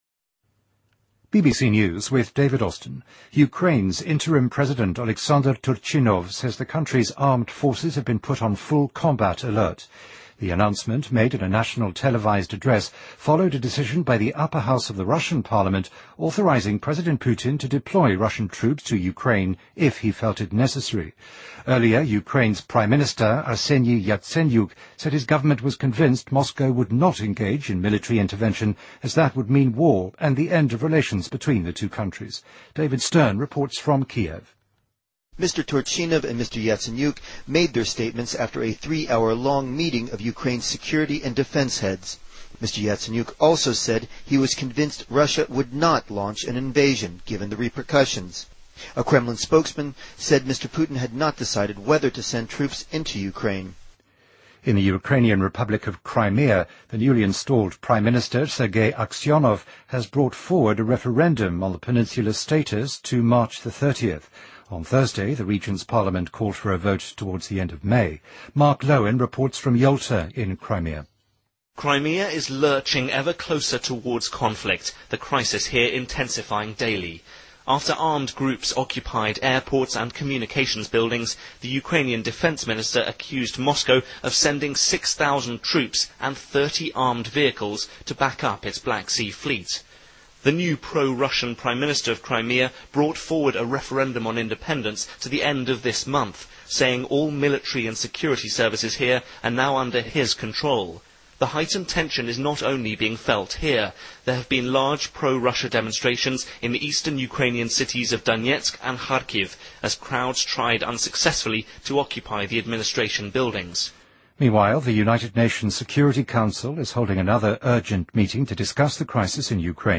BBC news,2014-03-02